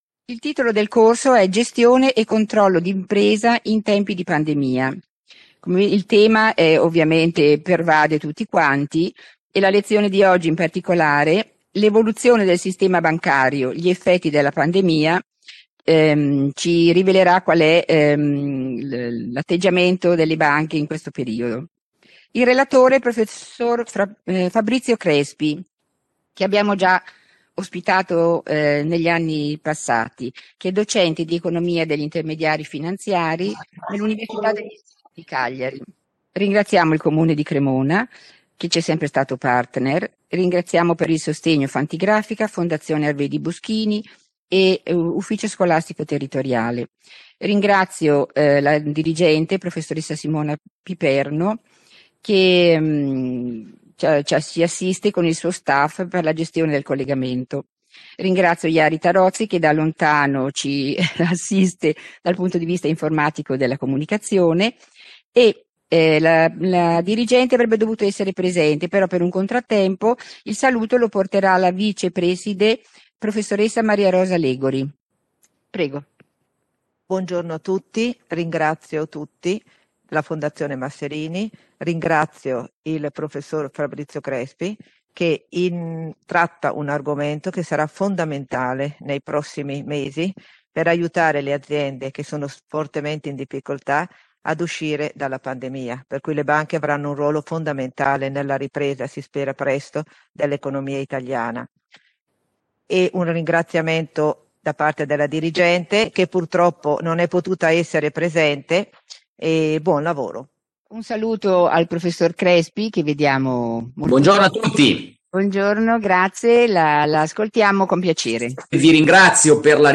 L’evoluzione del Sistema Bancario: gli effetti della Pandemia – Lezione – Fondazione Luigi Masserini